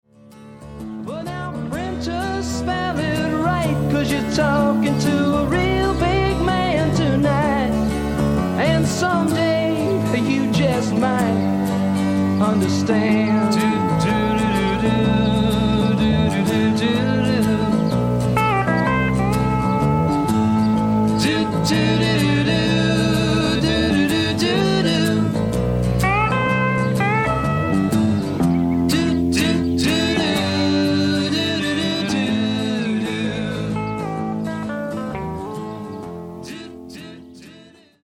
AMERICAN ROCK